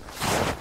snow.ogg